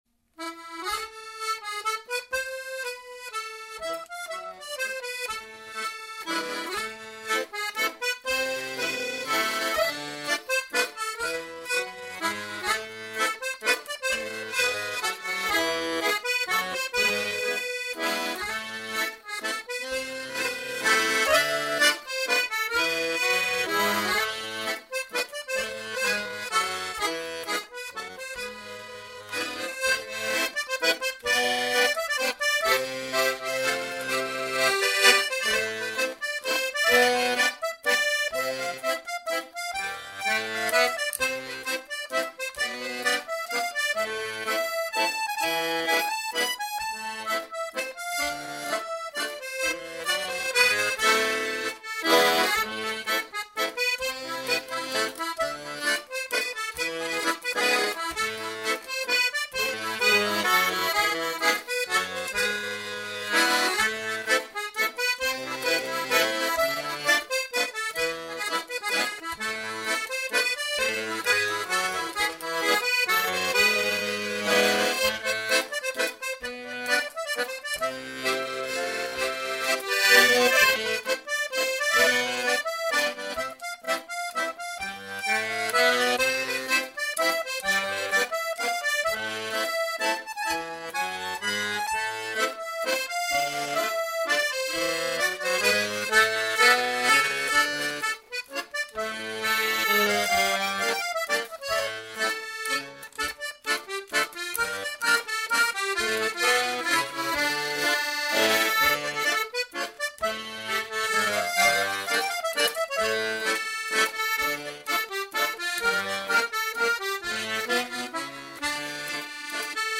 Clog Music 15% slower
Two Sammies waltzes 150bpm